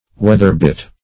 Meaning of weather-bit. weather-bit synonyms, pronunciation, spelling and more from Free Dictionary.
Search Result for " weather-bit" : The Collaborative International Dictionary of English v.0.48: Weather-bit \Weath"er-bit`\, n. (Naut.)